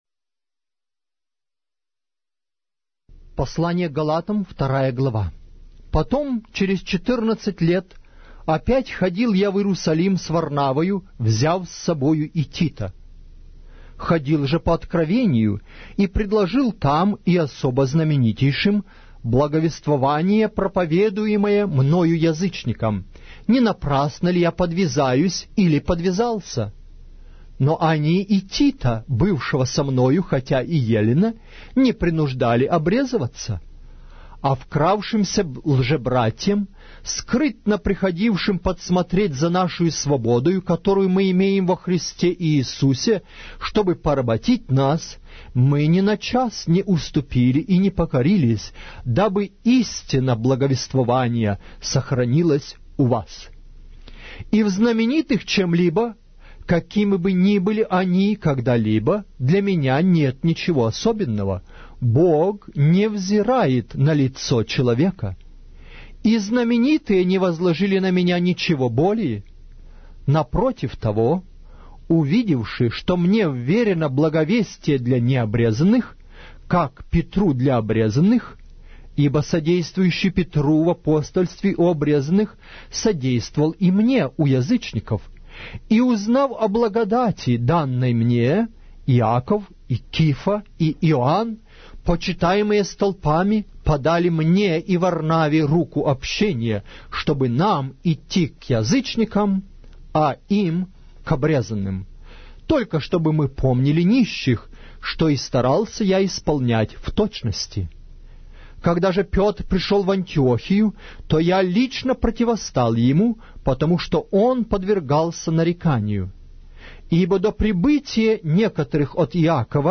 Автор аудиокниги: Аудио - Библия